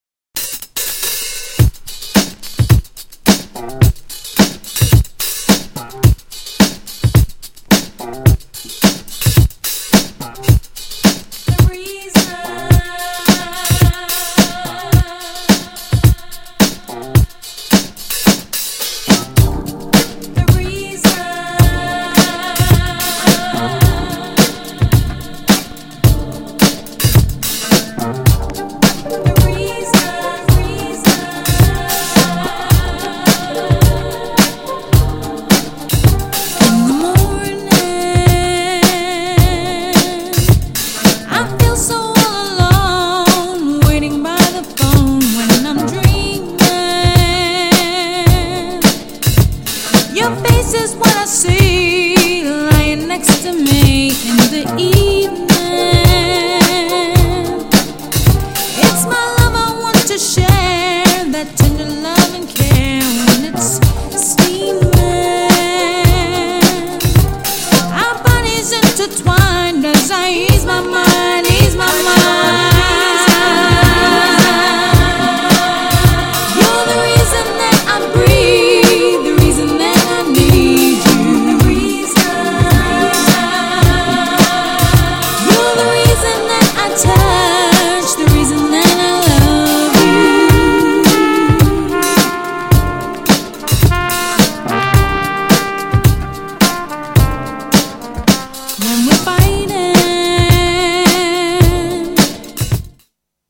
GROOVYなR&BのA面に
GENRE House
BPM 121〜125BPM
HOUSE_CLASSIC